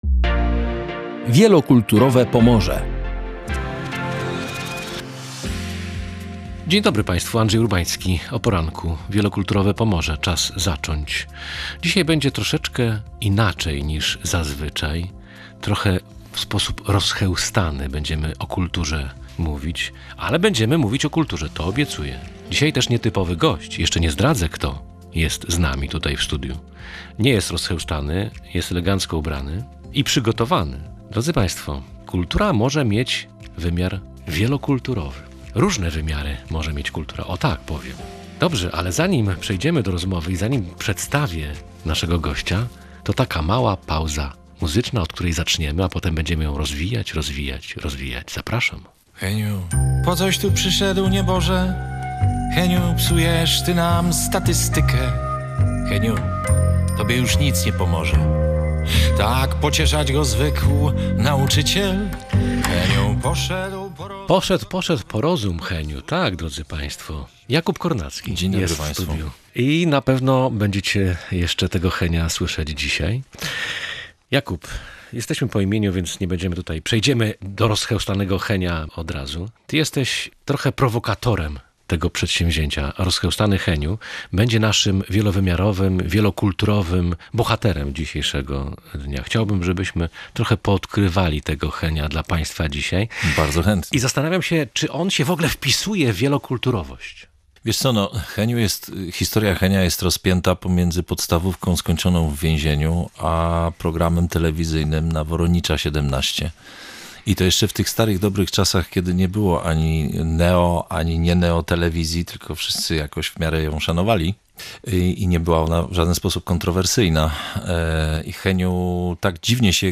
W tle pobrzmiewał „Rozchełstany Heniu”, który jest bohaterem teledysku i kampanii „nieWyGODNI”.